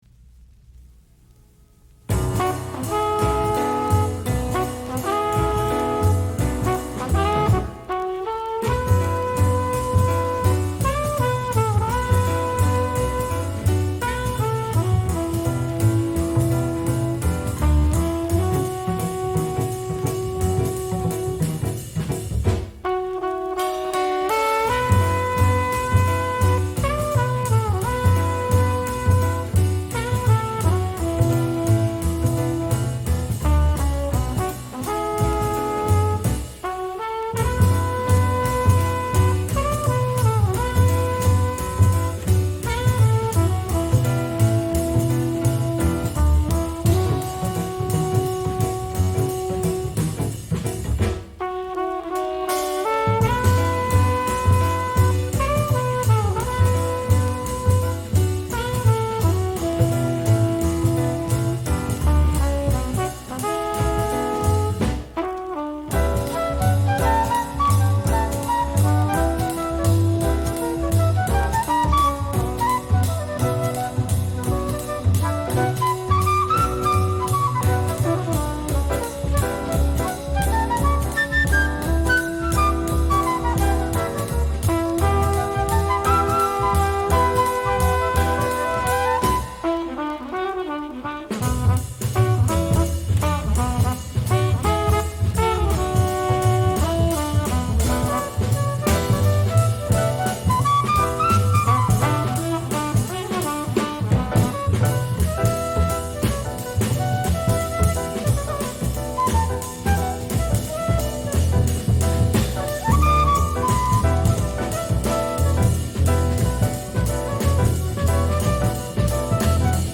being more of an accomplished jazz record in its own right.
Stereo